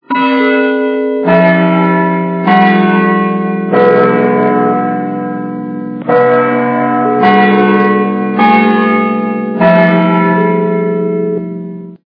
При прослушивании часы Биг Бен - Биение часов при получении СМС качество понижено и присутствуют гудки.
Звук часы Биг Бен - Биение часов при получении СМС